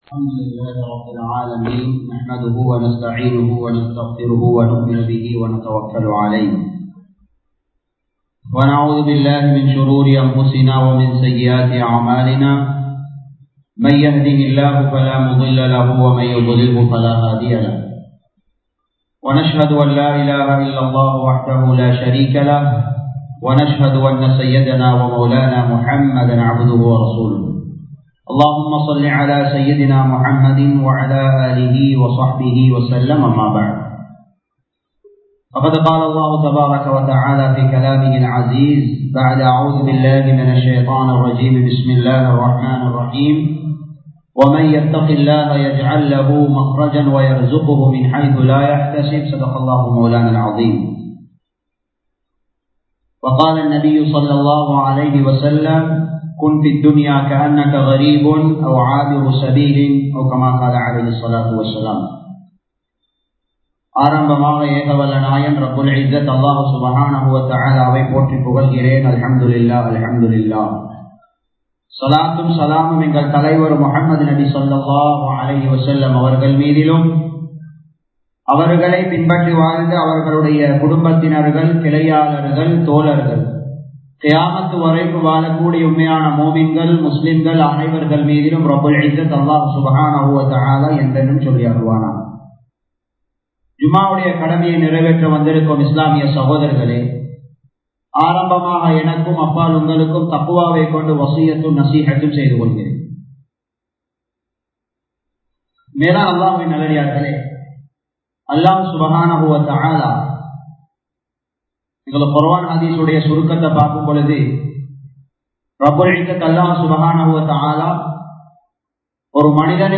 மற்றவர்களுக்கு உதவுங்கள் | Audio Bayans | All Ceylon Muslim Youth Community | Addalaichenai